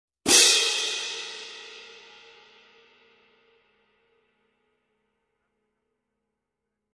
The Sabian 18 HHX New Symphonic French Cymbals have a cool shimmer and warm tone combine to create a musically full response.
Videos and Sound Clips SABIAN 18" HHX NEW SYMPHONIC FRENCH SABIAN 18" HHX NEW SYMPHONIC FRENCH (11819XN) Loud Crash 0 Customer Reviews Be the first to review this product.